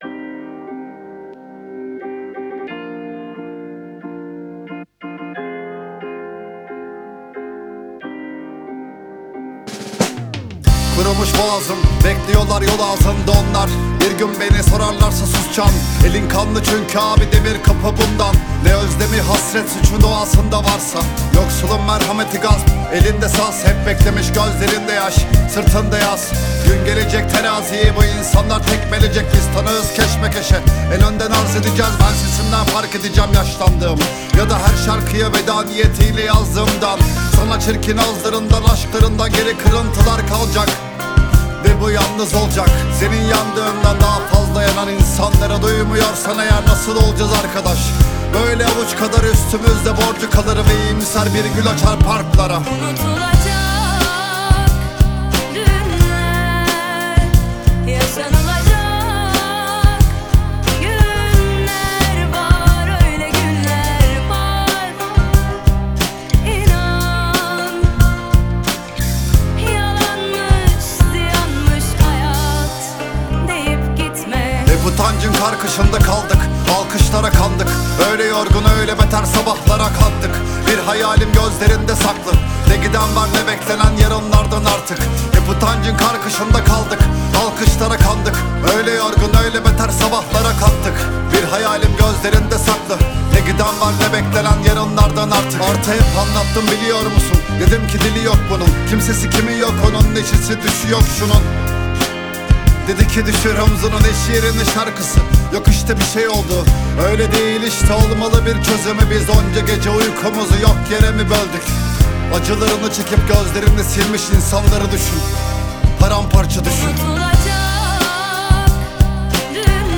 رپ ترکی